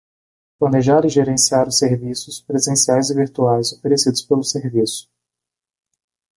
/ʒe.ɾẽ.siˈa(ʁ)/